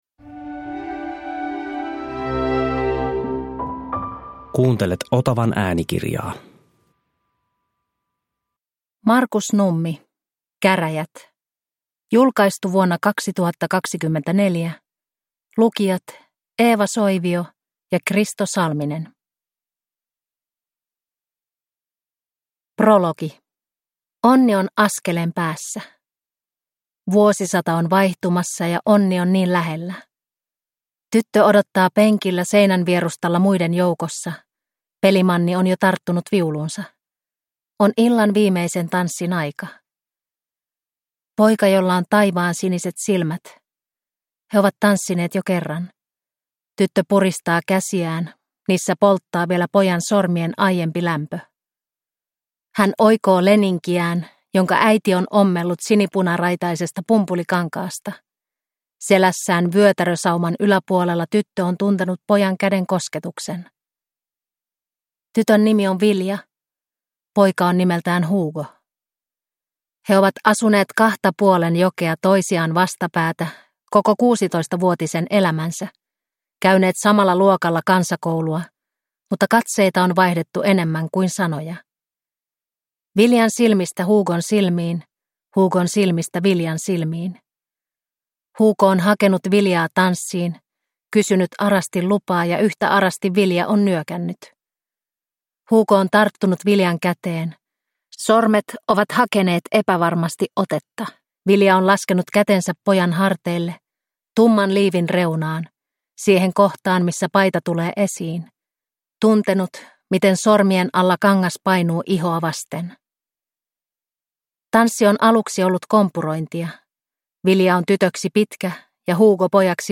Käräjät (ljudbok) av Markus Nummi